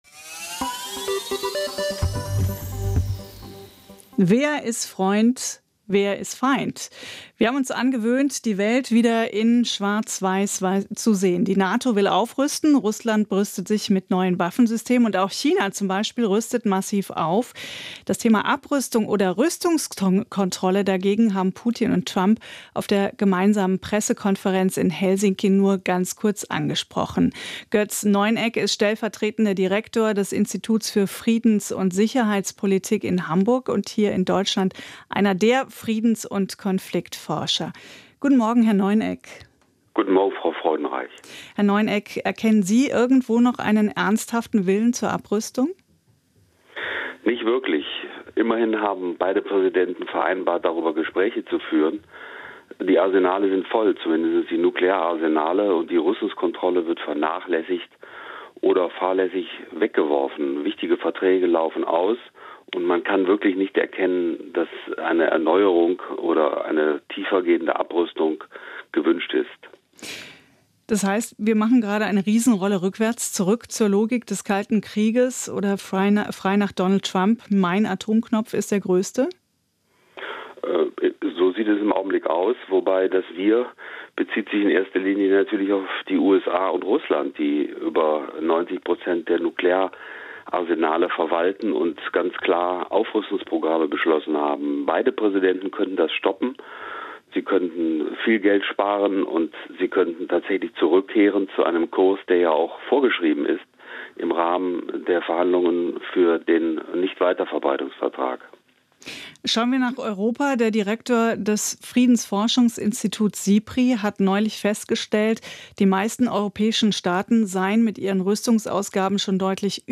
Warum wird nicht mehr über Abrüstung gesprochen? Interview im SWR-Hörfunk